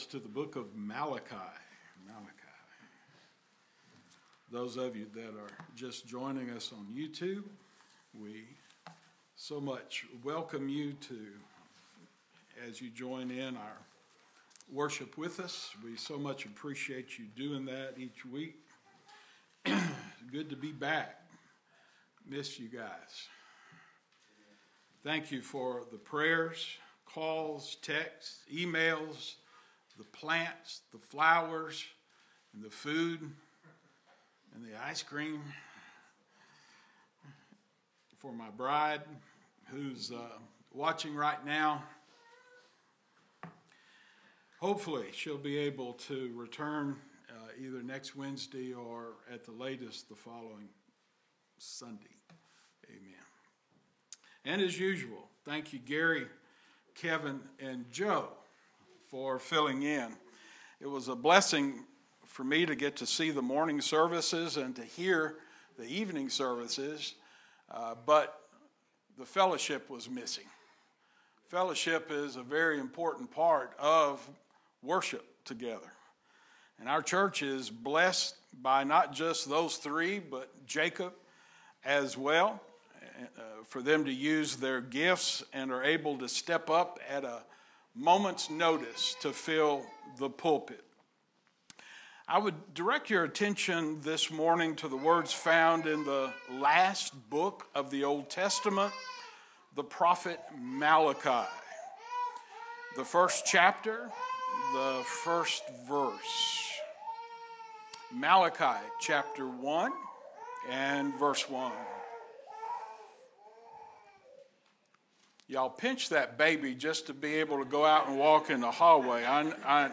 Sermons 2025